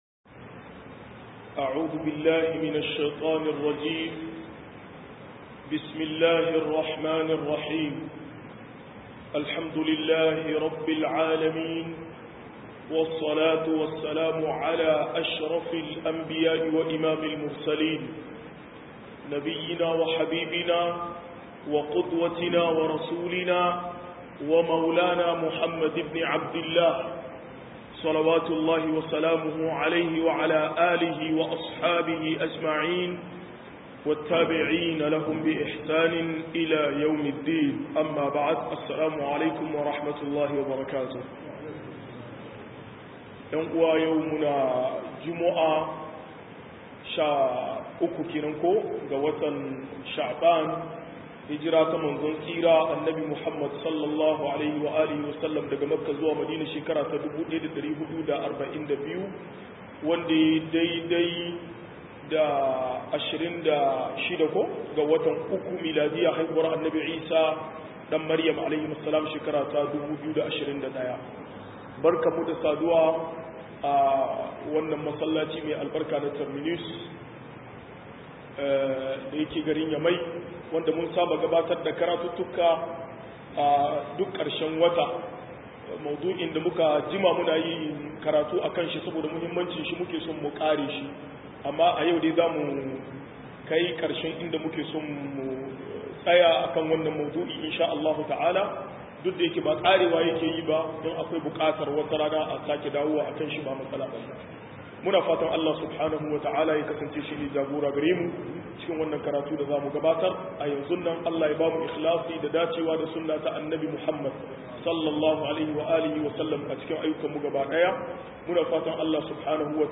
114-Mu uzuzijin Alkuraani 6 - MUHADARA